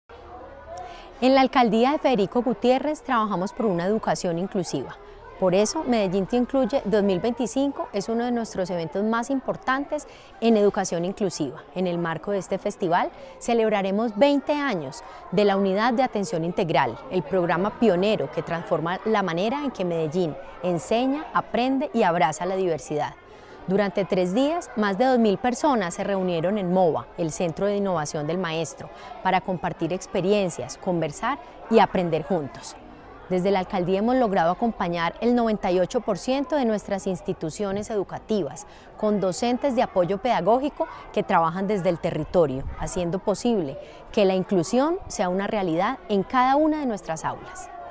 Declaraciones secretaria de Educación, Carolina Franco Giraldo Medellín Te Incluye se consolida como uno de los eventos más representativos de la ciudad en educación inclusiva.
Declaraciones-secretaria-de-Educacion-Carolina-Franco-Giraldo-1.mp3